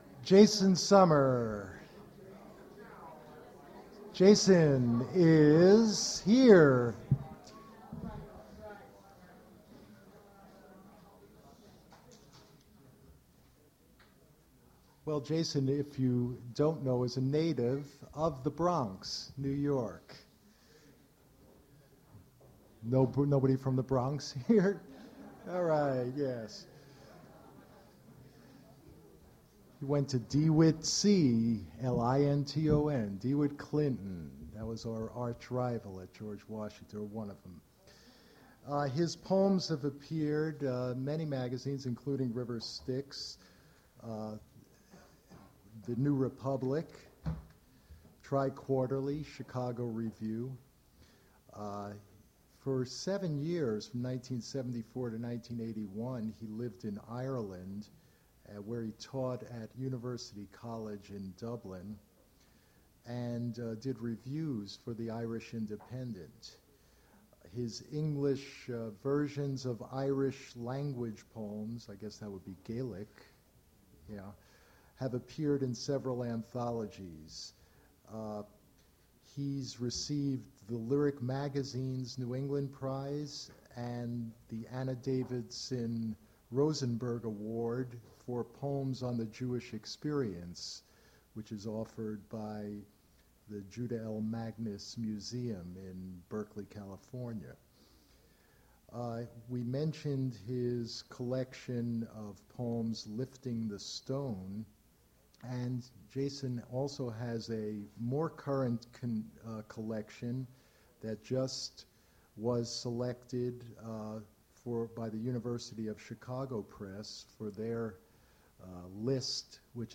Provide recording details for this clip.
reading his poetry at Duff's Restaurant generated from original audio cassette recording starts with introduction